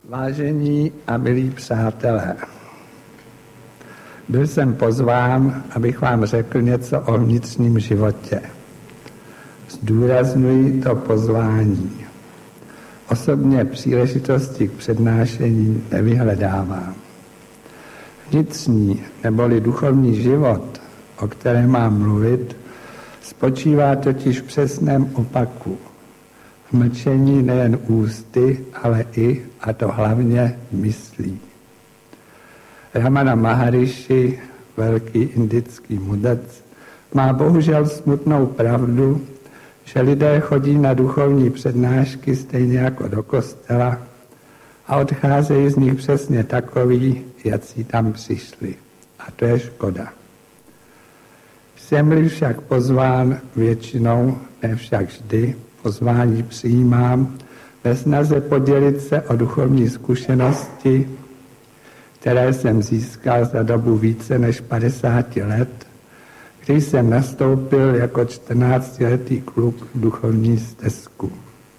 Metody vnitřního života - praxe jógy a mystiky. Přednáška v Táboře z října 1998. Nejedná se o studiovou nahrávku.